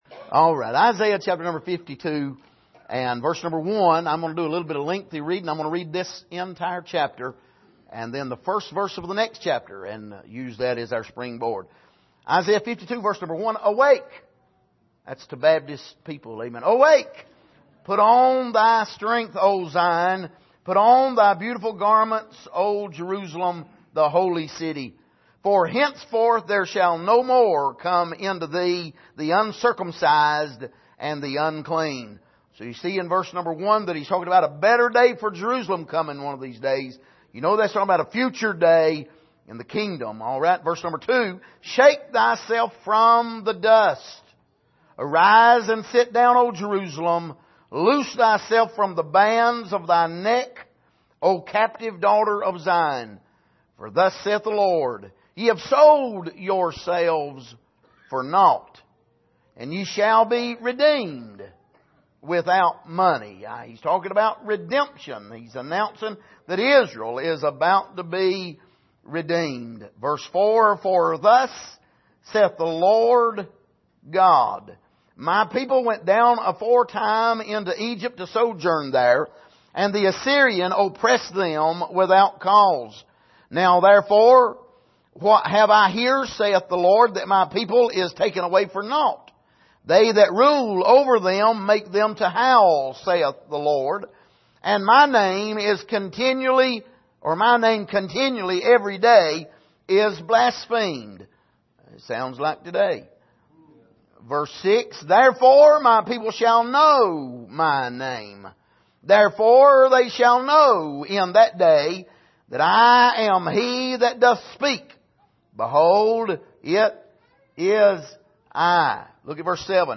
Passage: Isaiah 52:1-15 Service: Sunday Morning